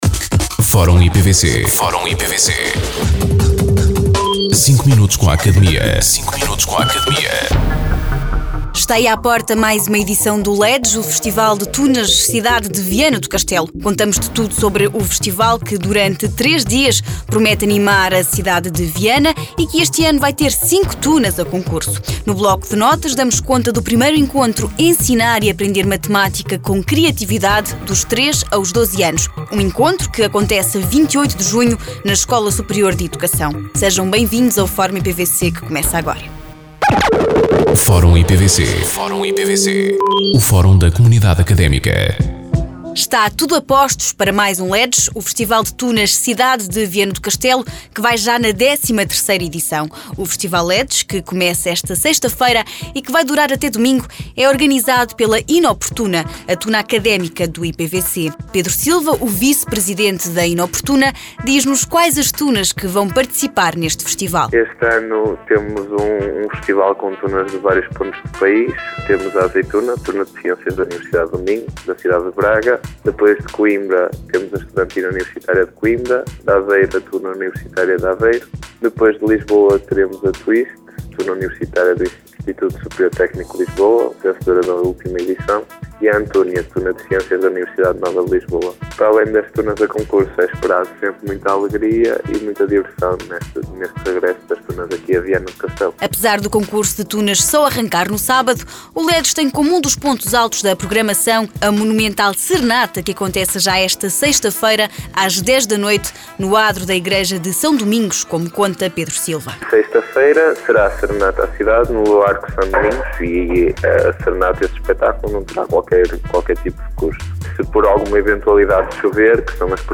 Entrevistados: